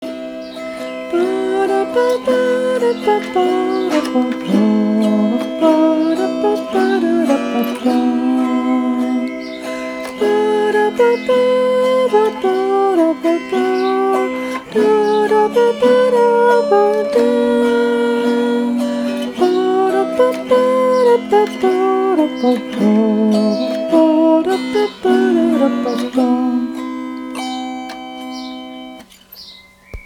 Diese Autoharp, die bisher zu bayrischer Volksmusik gespielt wurde, begleitet mich nun beim Singen der Lieder, zu denen ich Noten habe, beim Singen von …
• Kinderliedern, Protestsongs, Balladen etc. aus den Heften des Verlags „Student für Europa“ oder dem „Liederbuch“ von M. Korth und W. Schmöger